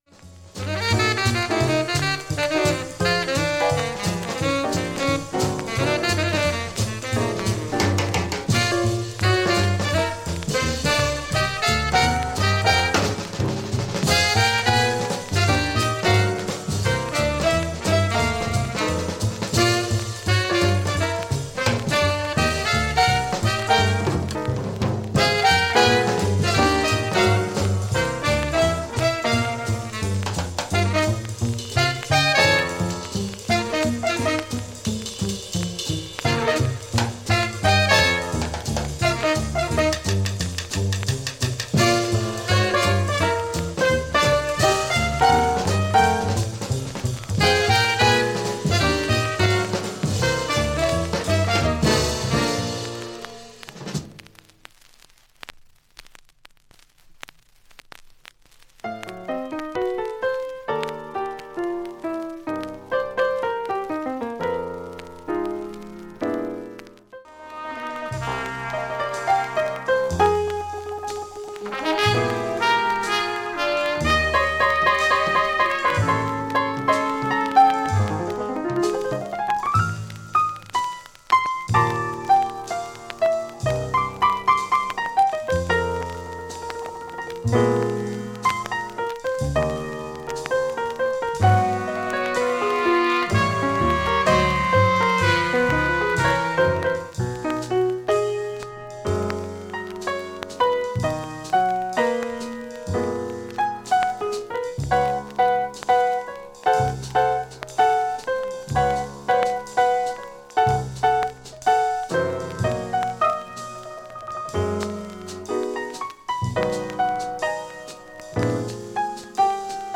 ジりパチも無く、深いプツもありません
普通に聴けます音質良好全曲試聴済み。
周回プツ出ますがごくかすかなレベルです
１２回までのかすかなプツが１２箇所
６回までのかすかなプツが８箇所
３回までのかすかなプツが７箇所
単発のかすかなプツが４箇所